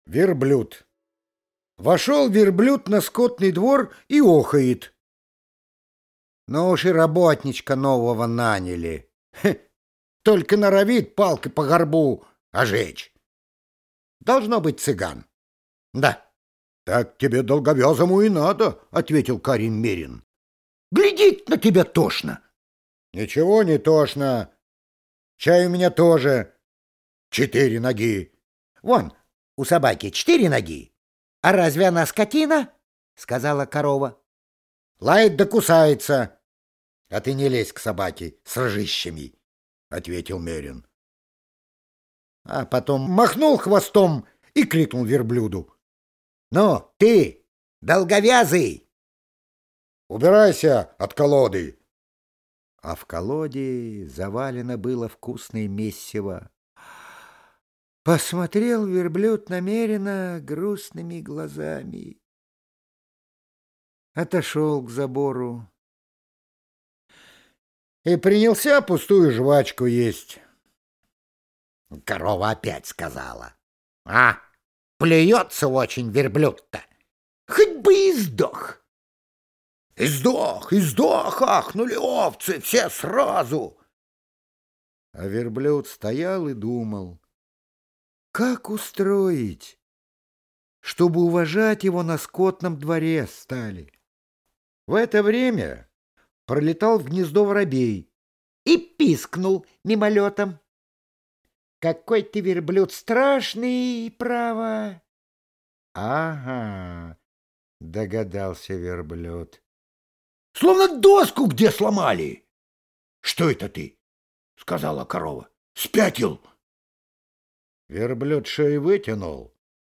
Верблюд – Толстой А.Н. (аудиоверсия)
Аудиокнига в разделах